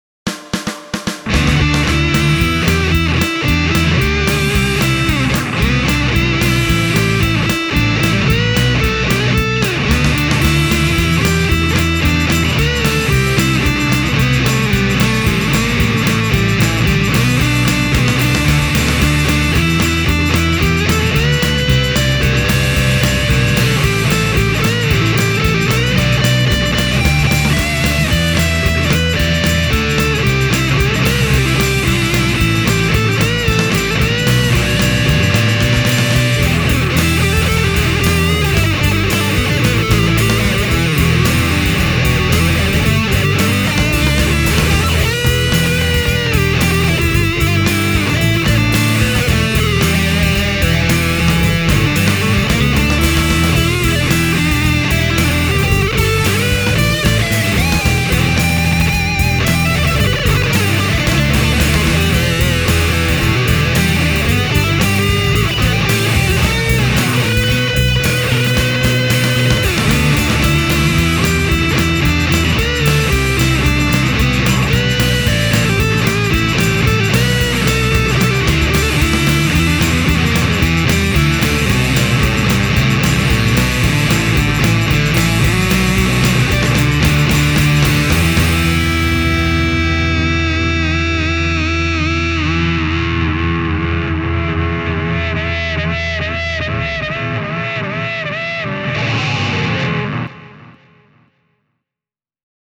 Videon ääniraidalla käytin erilaisia yhdistelmiä Fillmore 1970 -pedaalilaudan efekteistä. Kaikki raidat on soitettu Nash S-63 -kitaralla: